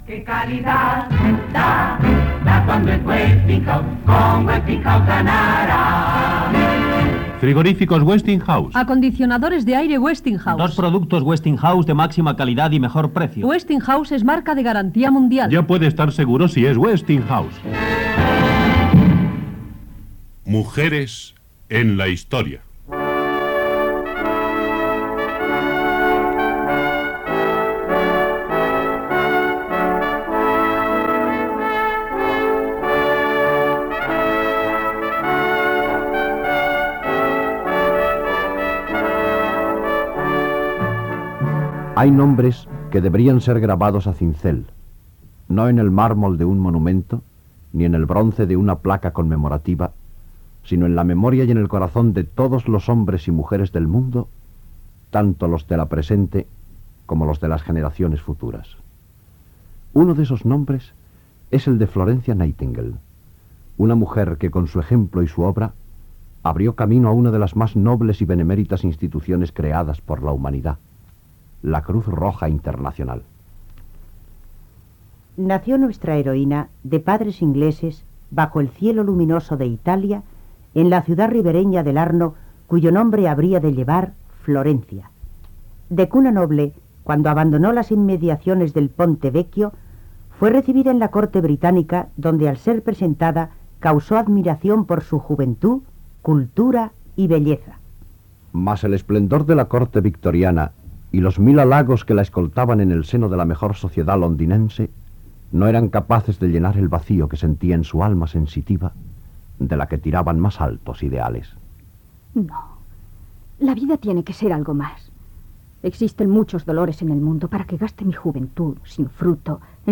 Secció "Mujeres en la historia". Publicitat i espai dedicat a la infermera Florence Nightingale, que va contribuir en la creació de la Creu Roja Britànica a l'any 1870, publicitat
Entreteniment